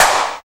112 CLAP SNR.wav